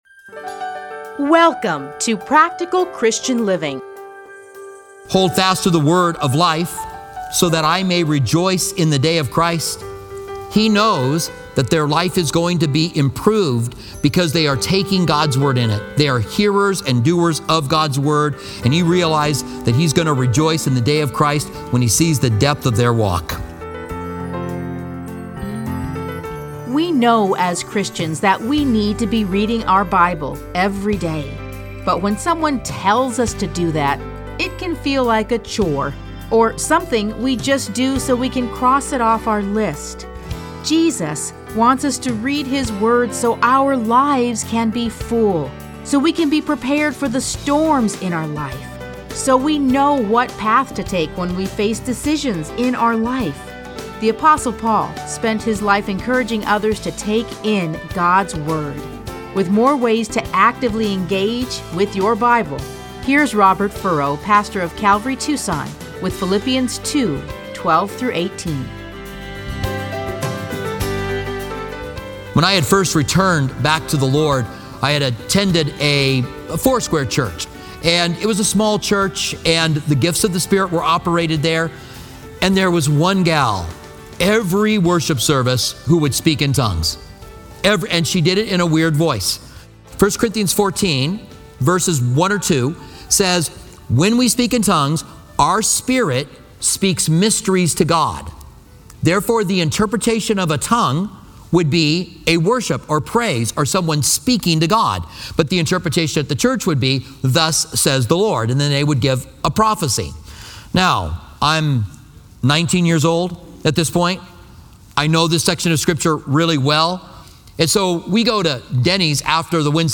Practical Christian Living. Listen to a teaching from A Study in Philippians 2:12-18.